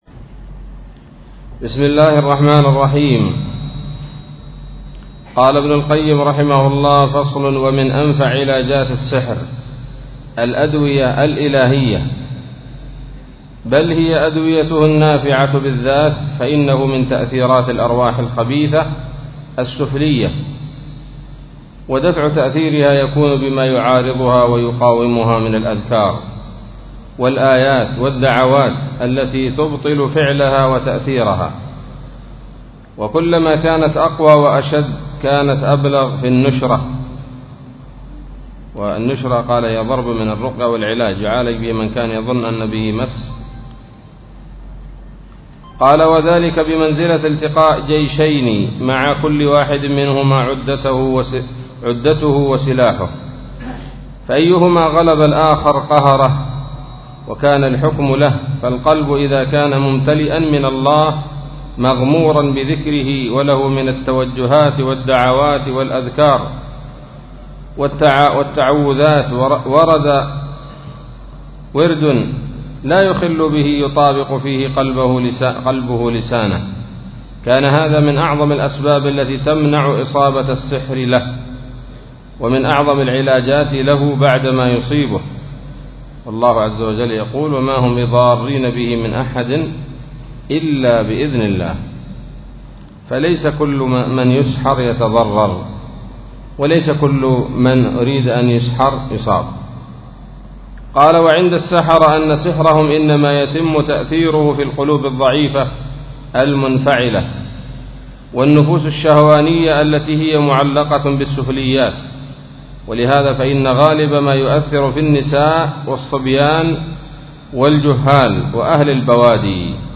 الدرس الخامس والثلاثون من كتاب الطب النبوي لابن القيم